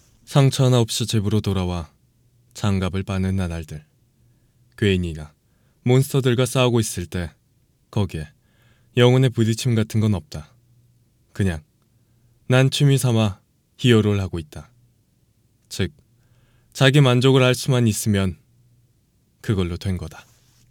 [남자성우] 남성의 쿨한 목소리 입니다! (나레이션, 성우, 캐릭터)
성우 공부나 연극과 같은 연기를 공부한 지 4년 차가 넘어서 여러 상황의 목소리 연기가 가능합니다!
-녹음은 방음 부스에서 진행이 되며 외적인 소리로 인해 문제가 생길 시 다시 재녹음을 해드립니다!